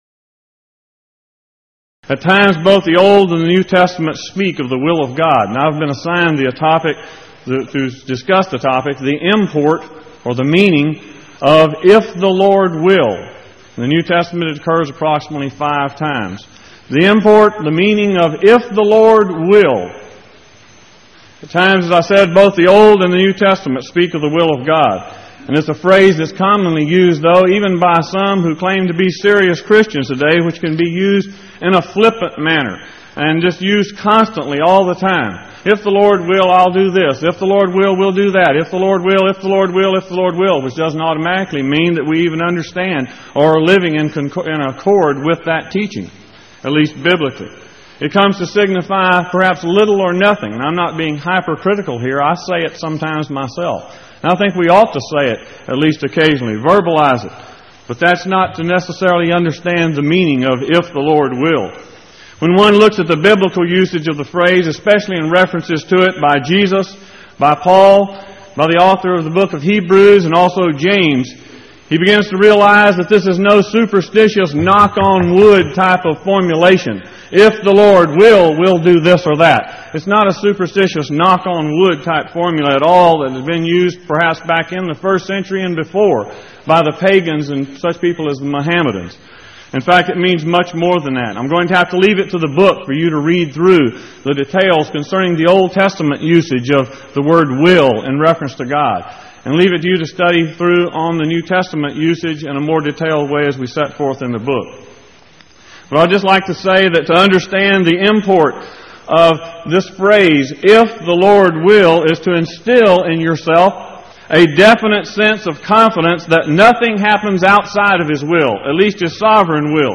Series: Power Lectures Event: 1989 Power Lectures